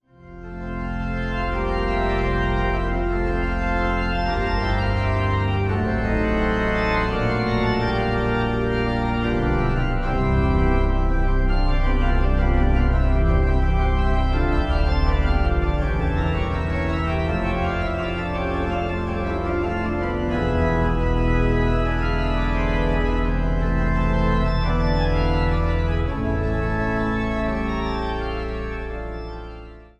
Schuke-Orgel der Kirche St. Divi Blasii Mühlhausen